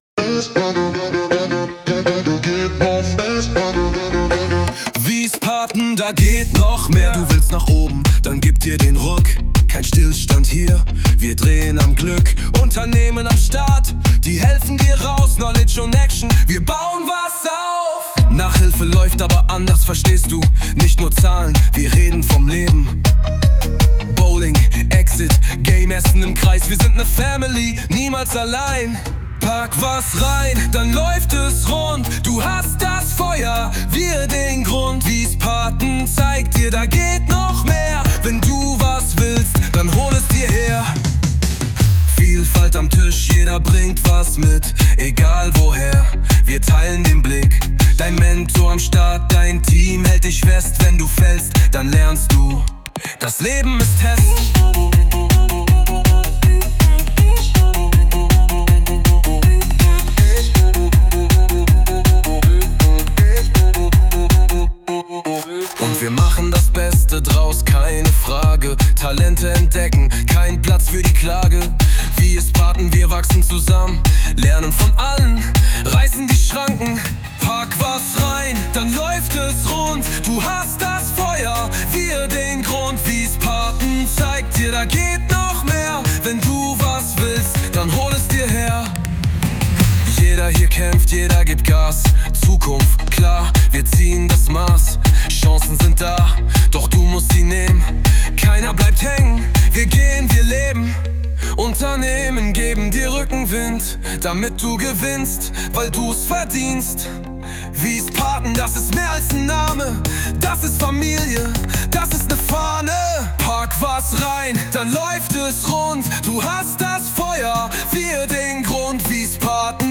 WiesPaten hat jetzt einen eigenen HipHop!
Die Musik hat die Musik-KI “SUNO” erfunden.
Wir finden, der Song macht Laune und beschreibt perfekt, was WiesPaten kann!
WiesPaten-Da-geht-noch-mehr-HIPHOP-SONG_2025.mp3